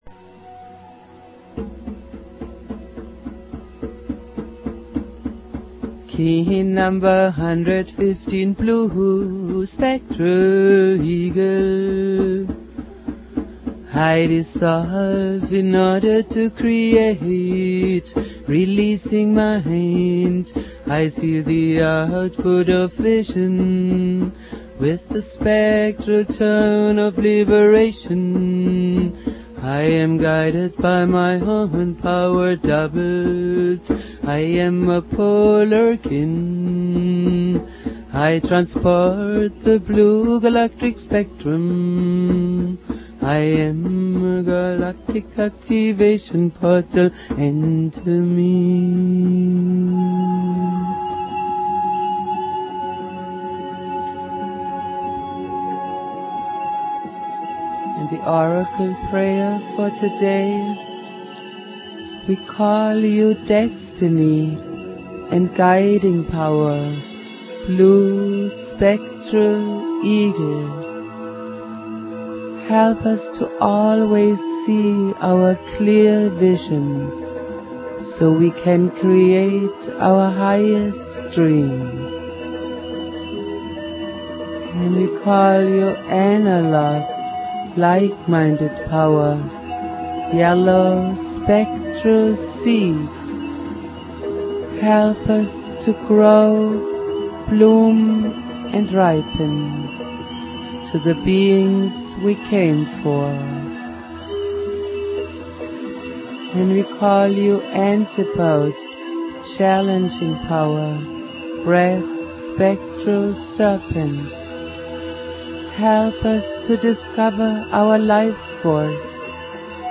Prayer
produced at High Flowing Recording Studio
Jose's spirit and teachings go on Jose Argüelles playing flute.